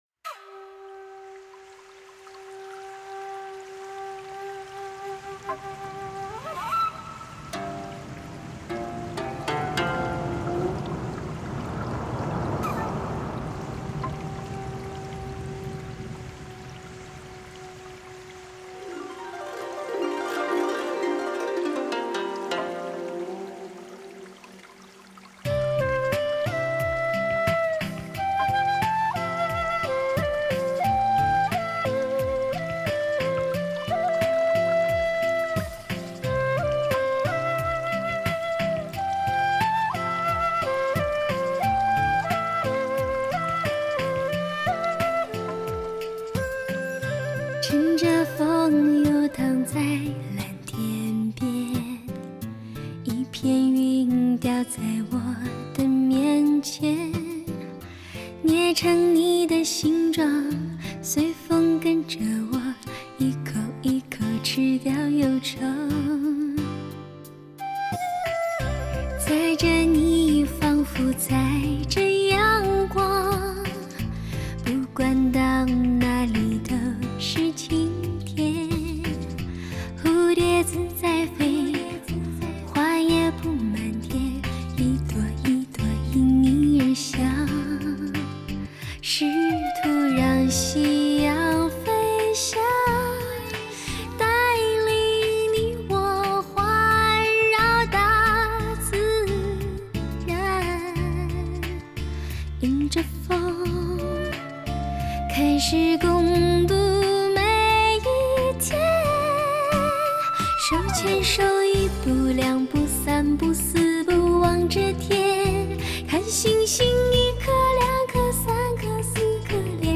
dts 5.1声道音乐
采用美国dts5.1顶级编码器，令各种乐器的音质、结像、空间感及环绕立体声动态对比大大提高，比原版更靓声。